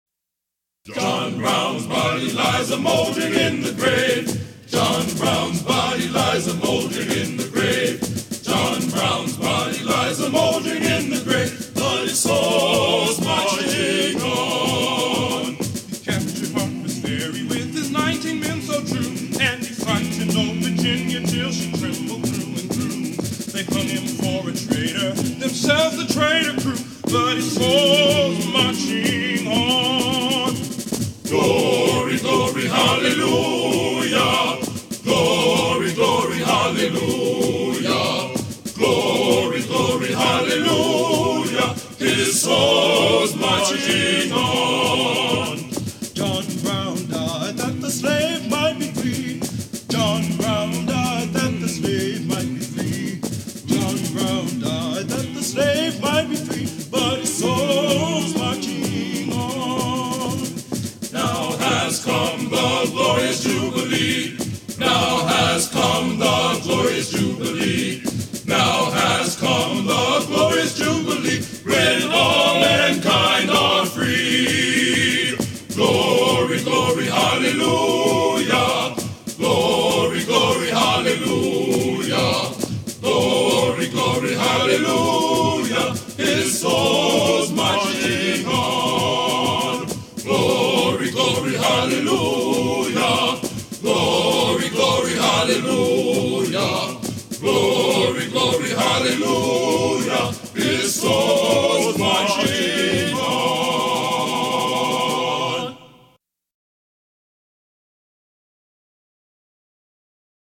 This song, “John Brown’s Body,” was written in part to tease a soldier named John Brown who shared a name with the famous abolitionist. The song, however, grew to become one of the Union Army’s most popular marching tunes and the song changed in meaning to focus exclusively on the well-known Brown.